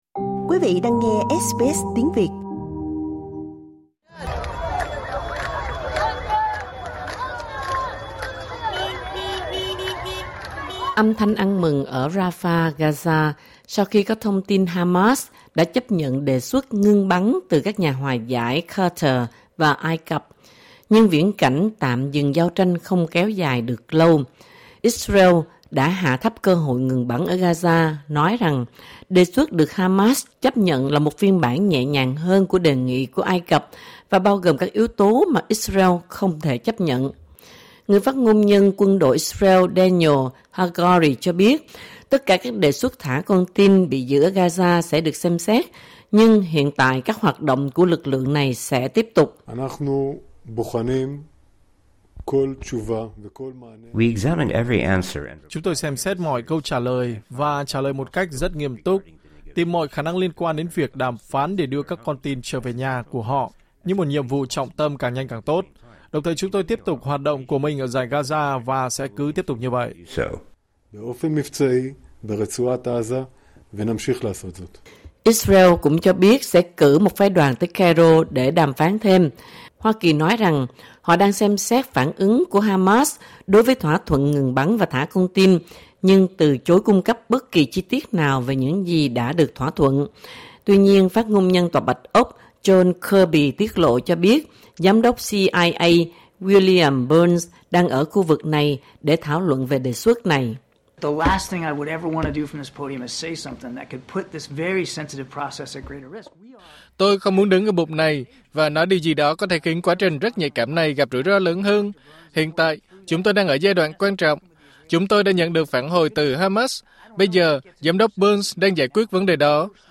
Âm thanh ăn mừng ở Rafah, Gaza sau khi có thông tin Hamas đã chấp nhận đề xuất ngừng bắn từ các nhà hòa giải Qatar và Ai Cập.